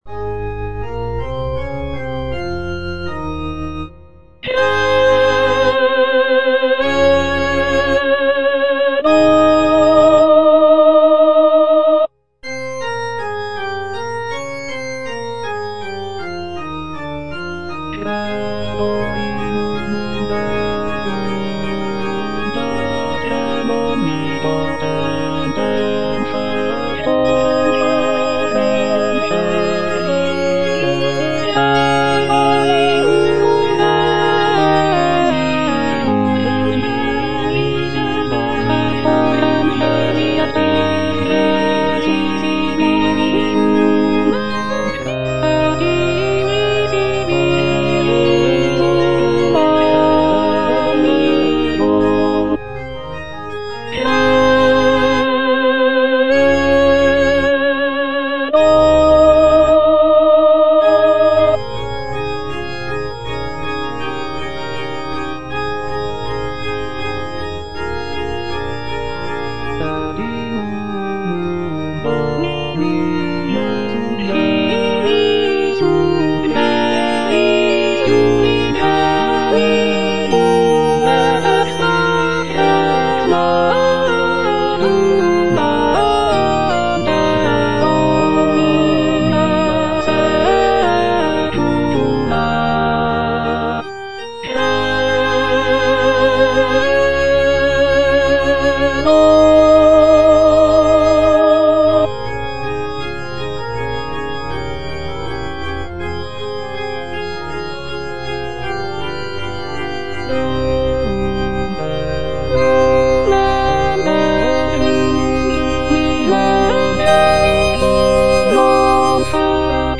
C.M. VON WEBER - MISSA SANCTA NO.1 Credo - Alto (Emphasised voice and other voices) Ads stop: auto-stop